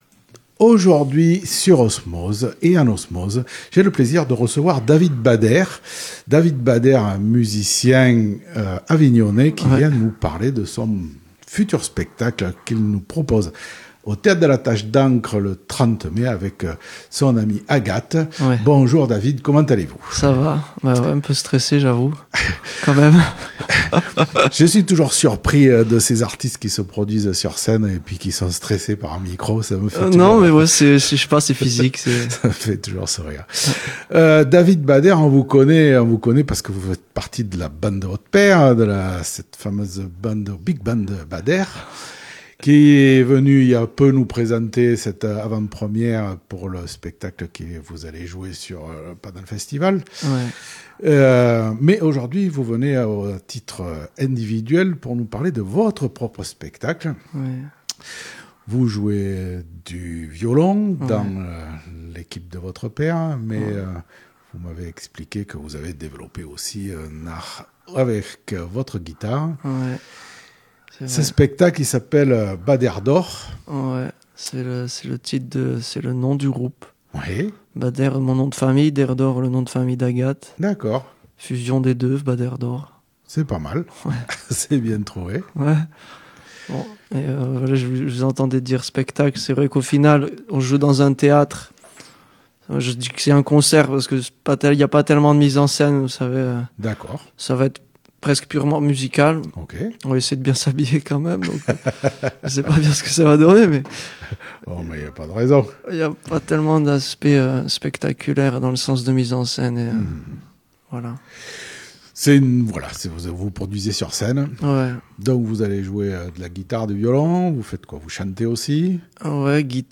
a capella, simple et émouvant